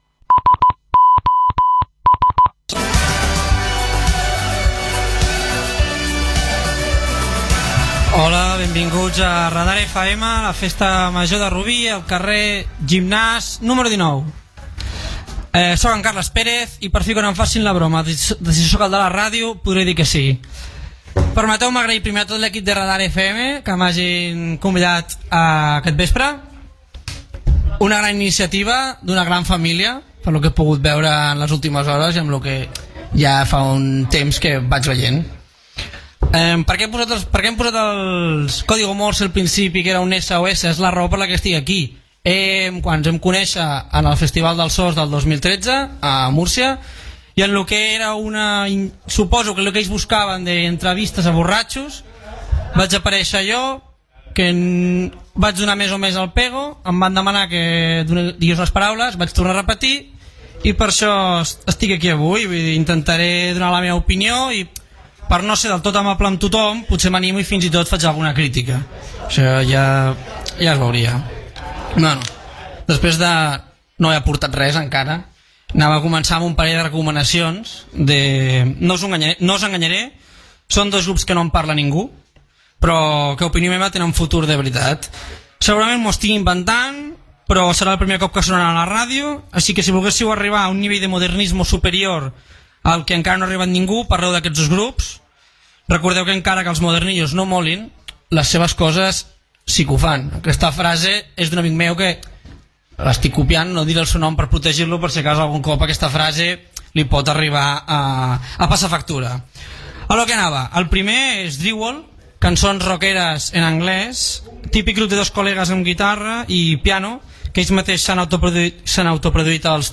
SOS en Morse